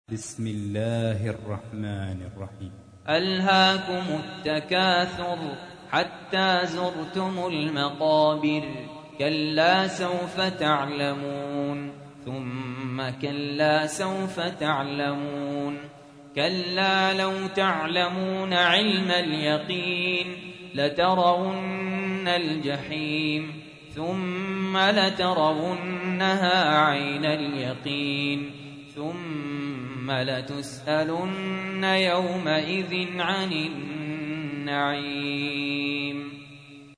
تحميل : 102. سورة التكاثر / القارئ سهل ياسين / القرآن الكريم / موقع يا حسين